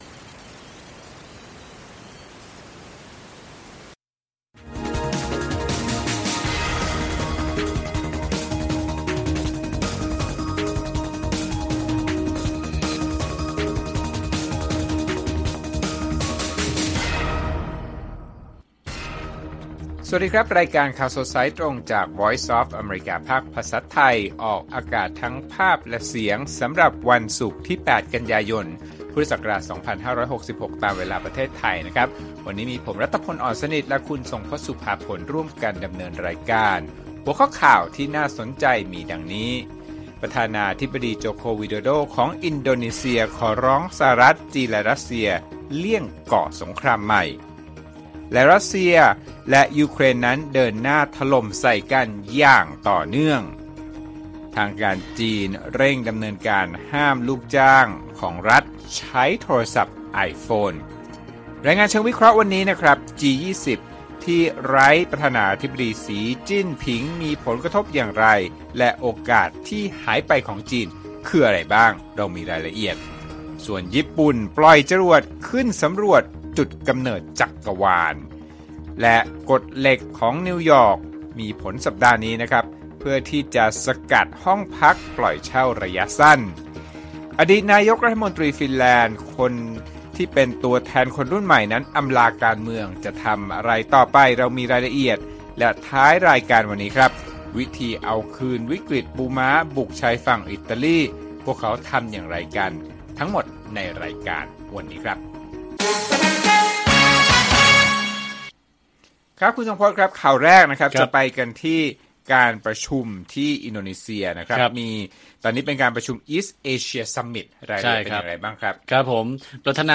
ข่าวสดสายตรงจากวีโอเอไทย 6:30 – 7:00 น. วันที่ 8 ก.ย. 2566